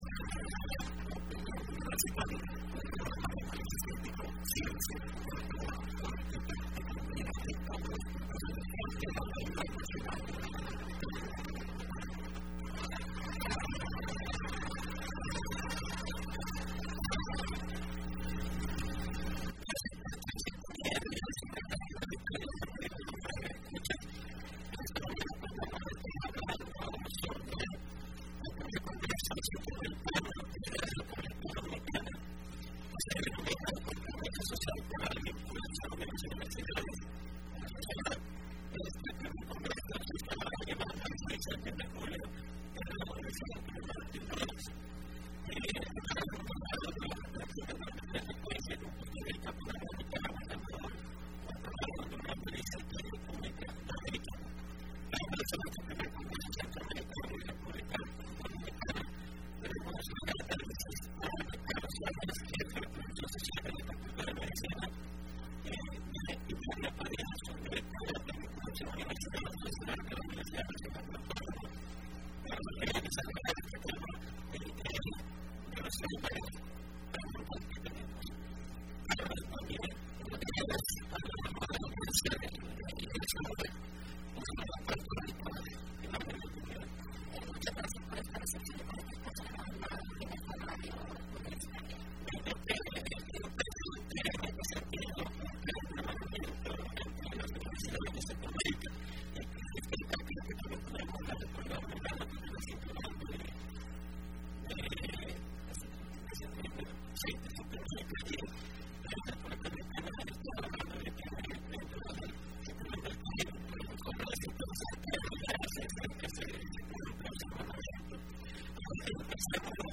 Entrevista Opinión Universitaria (28 mayo 2015): Primer congreso Centroamericano y de República Dominicana de vinculación de las Universidades Públicas